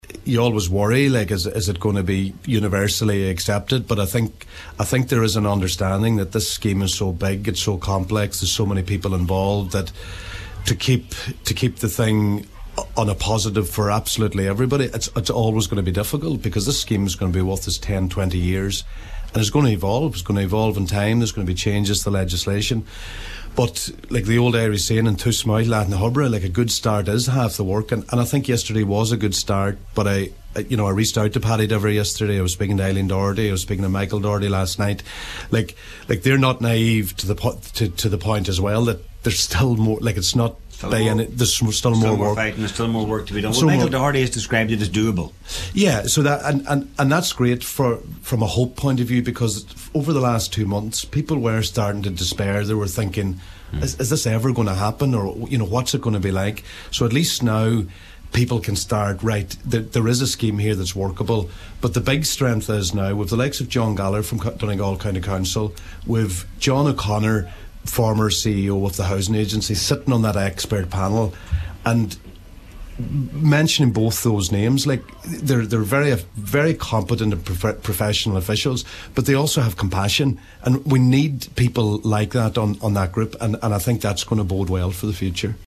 at the official launch of the new Mulroy Drive initiative.